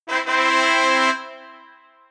fanfare.ogg